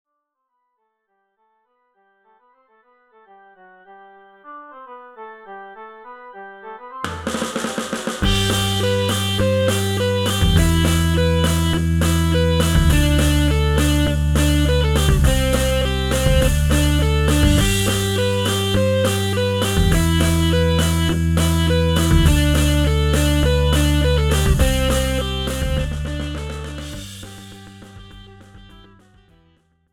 This is an instrumental backing track cover.
• Key – G
• Without Backing Vocals
• No Fade